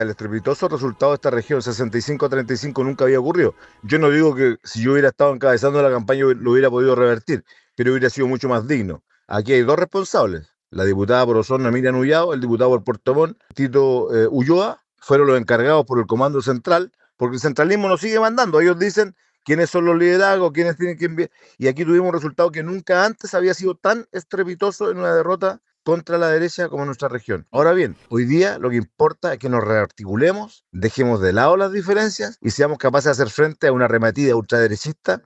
Cifras que no dejaron indiferente a los senadores de la zona, que en conversación con La Radio, dieron cuenta de sus proyecciones y análisis, a solo minutos de conocerse que el republicano será el próximo presidente.